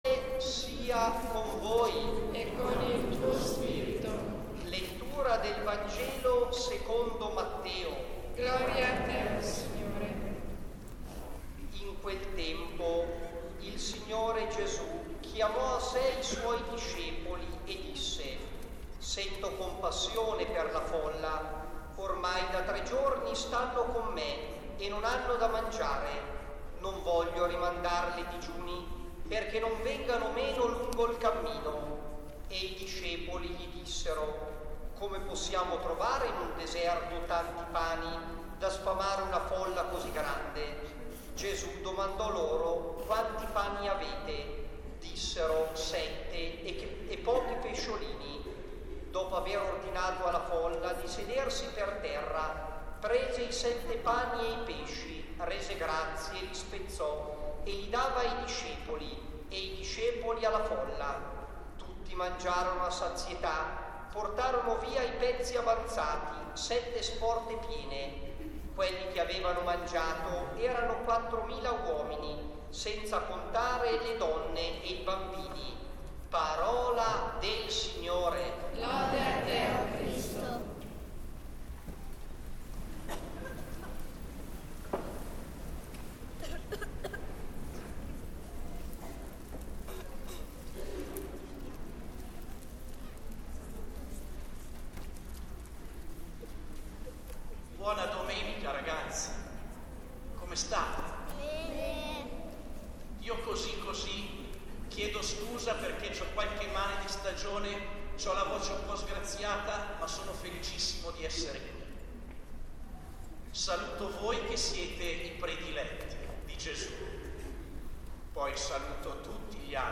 Santa Messa del 24 gennaio 2016